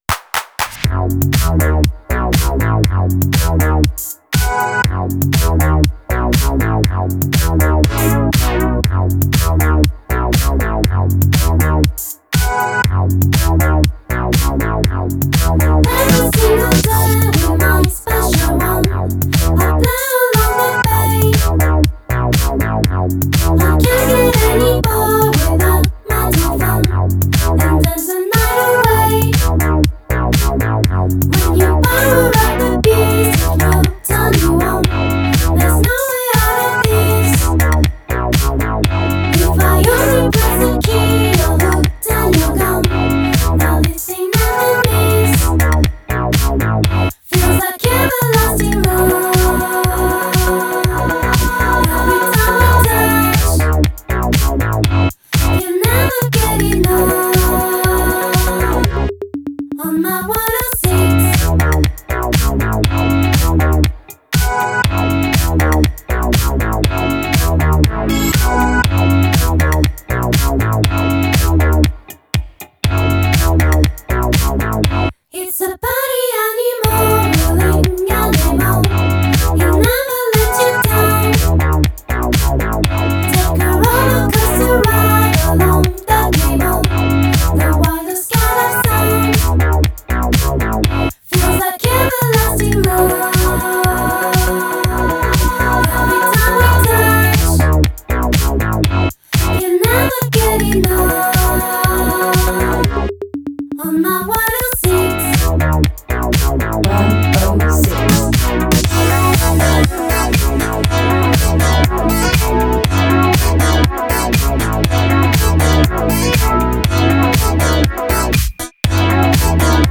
more on the disco end of the electronic spectrum
Electronic